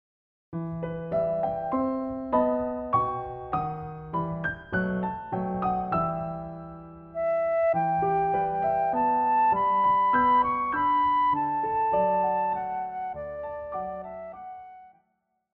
古典
長笛
鋼琴
獨奏與伴奏
有主奏
有節拍器